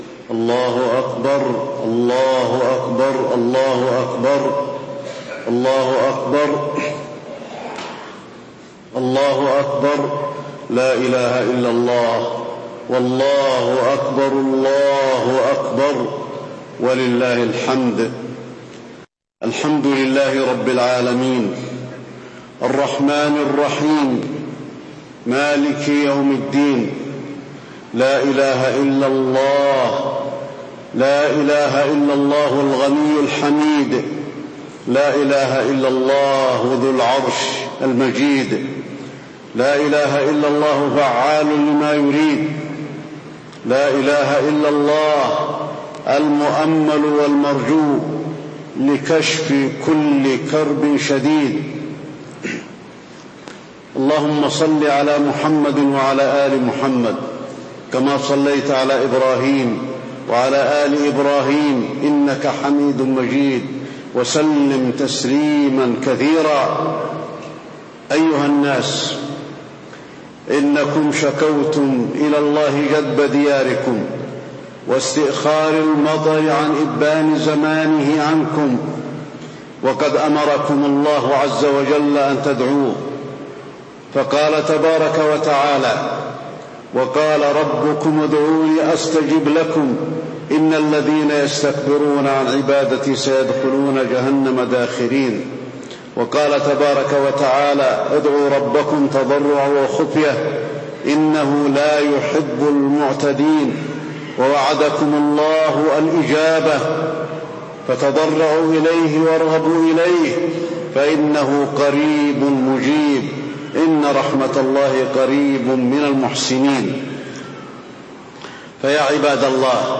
خطبة الاستسقاء - المدينة- الشيخ علي الحذيفي - الموقع الرسمي لرئاسة الشؤون الدينية بالمسجد النبوي والمسجد الحرام
تاريخ النشر ١٦ محرم ١٤٣٧ هـ المكان: المسجد النبوي الشيخ: فضيلة الشيخ د. علي بن عبدالرحمن الحذيفي فضيلة الشيخ د. علي بن عبدالرحمن الحذيفي خطبة الاستسقاء - المدينة- الشيخ علي الحذيفي